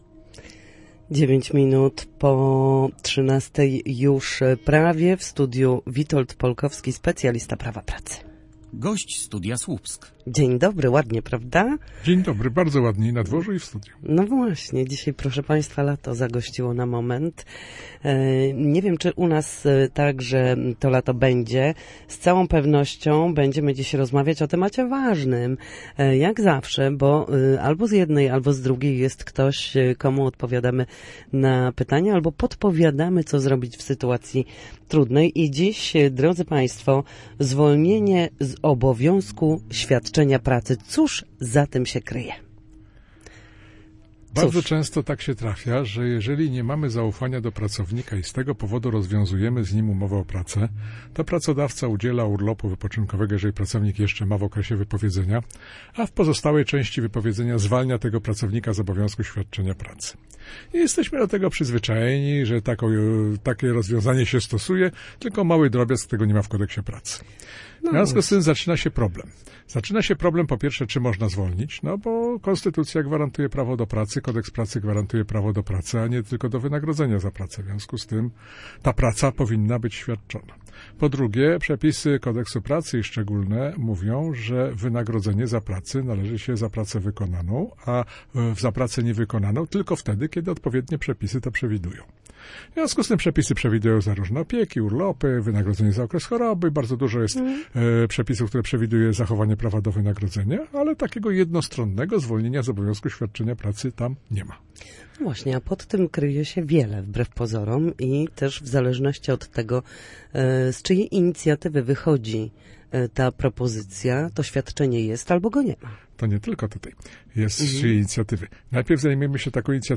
W każdy wtorek po godzinie 13 na antenie Studia Słupsk przybliżamy państwu zagadnienia dotyczące Prawa pracy.